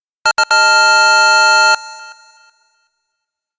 Alarm indicating the multiplayer minigame is over